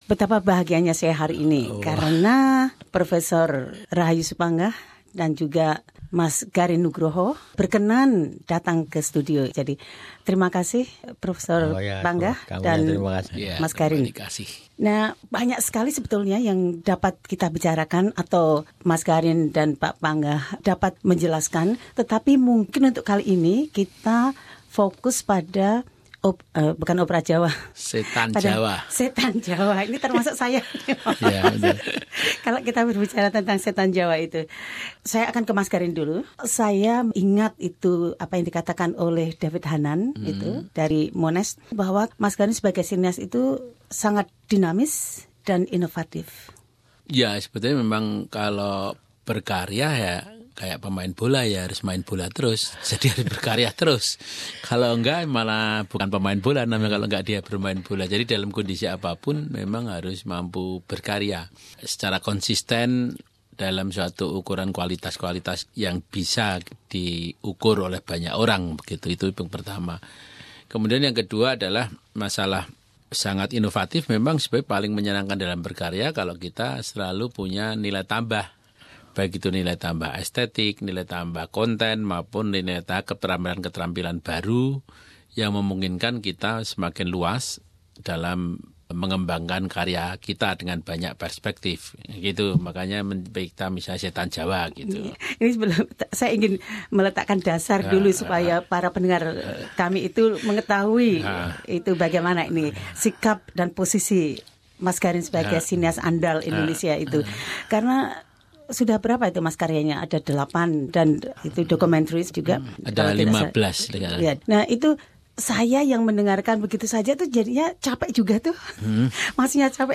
Sutradara film Garin Nugraha dan composer Profesor Rahayu Supanggah berbicara tentang AsiaTopa dan Setan Jawa dan karya kolaborasinya dengan composer Iain Grandage, yang menggabungkan film, gamelan dan musik simfoni.